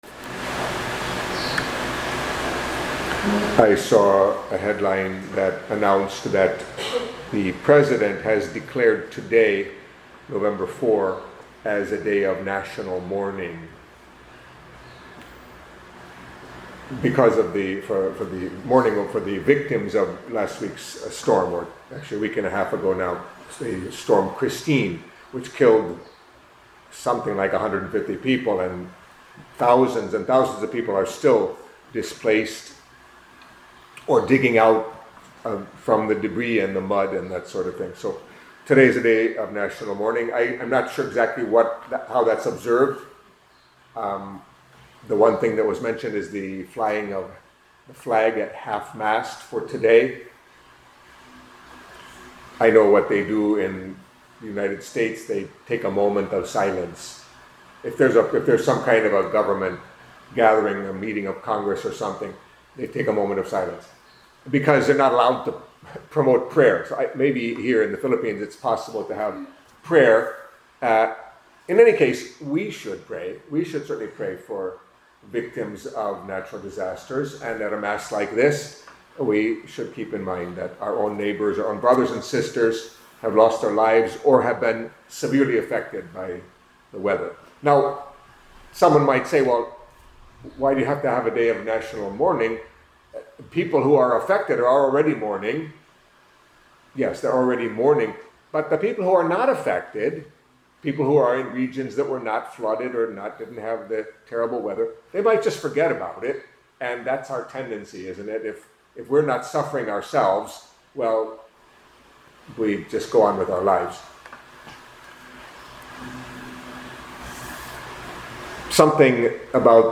Catholic Mass homily for Monday of the Thirty-First Week in Ordinary Time